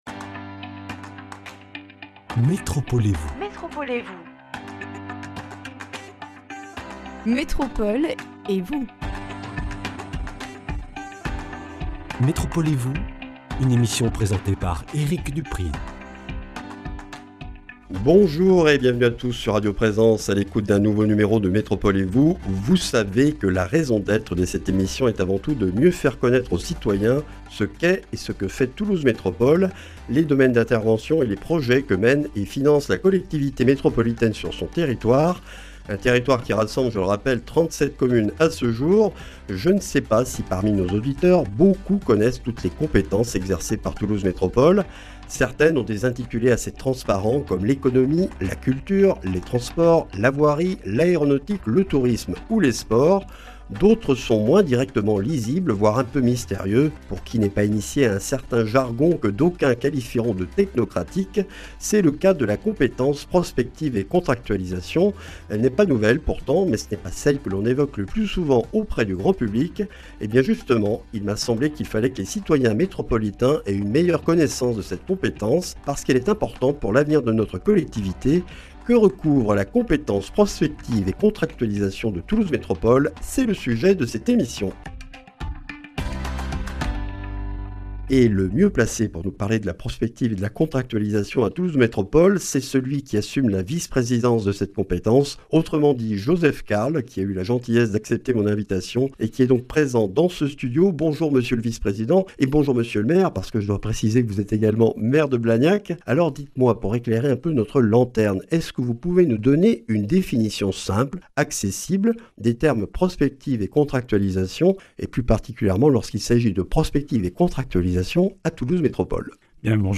REDIFFUSION : Un numéro pour découvrir la compétence Prospective et Contractualisation de Toulouse Métropole, avec Joseph Carles, maire de Blagnac, vice-président de Toulouse Métropole chargé de la Prospective et de la Contractualisation. Une compétence mal connue mais cruciale pour affronter la crise en cours et s’adapter aux évolutions qu’elle va entraîner dans notre société et notre économie.